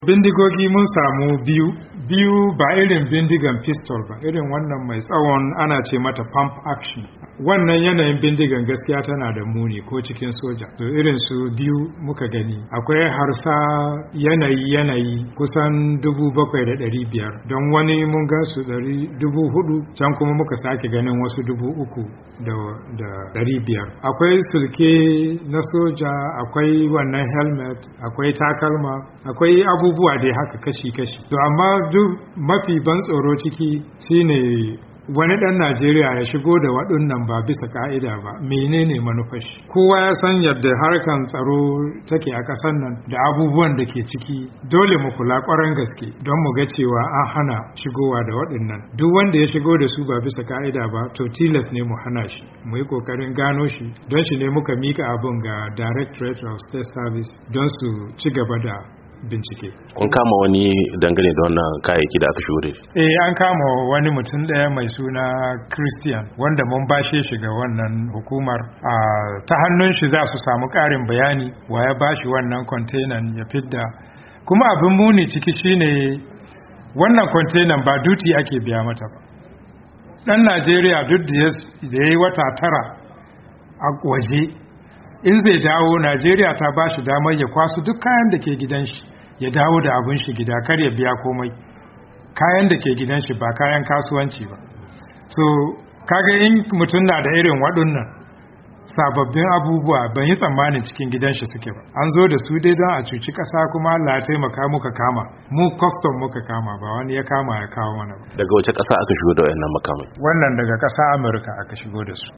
Domin karin bayani saurari tattaunawar da shugaban Kwastam Alhaji Bashar Muhammad.